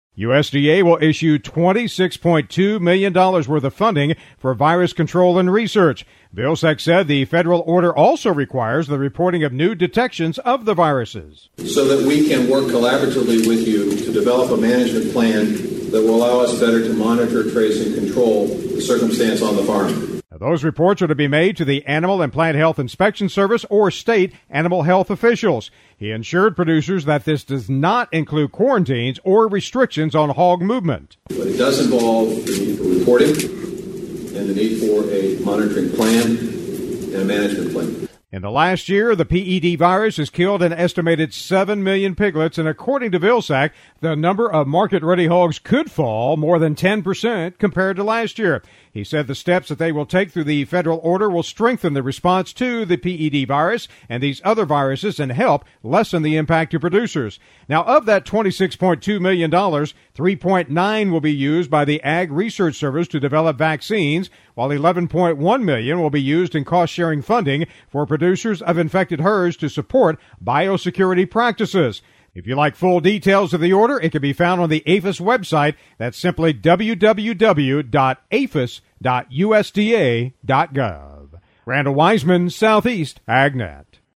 While speaking at World Pork Expo this week, U.S. Ag Secretary Tom Vilsack announced funding to help combat the Porcine Epidemic Diarrhea Virus. He also issued a federal order to help combat the significant impact producers are facing due to PED virus and the porcine deltacoronavirus.